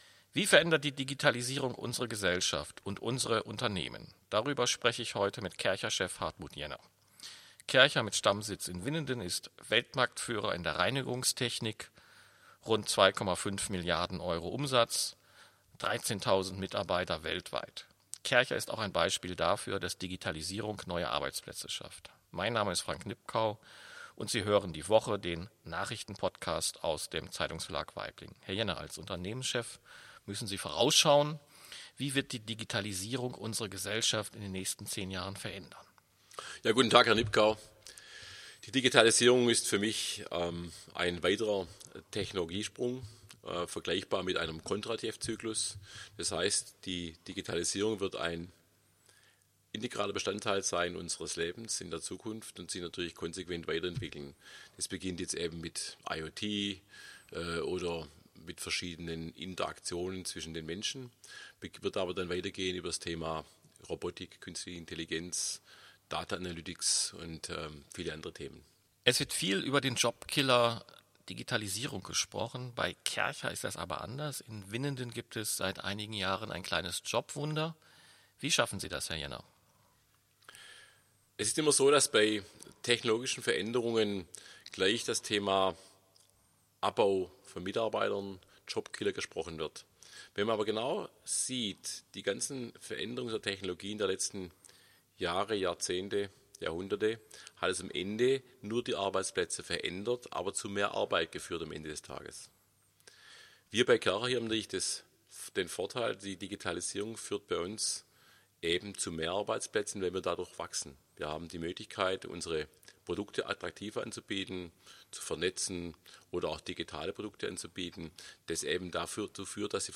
Die Woche ist der Nachrichten-Podcast aus dem Zeitungsverlag Waiblingen.